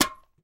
Звуки лейки
Звук опрокинутой металлической лейки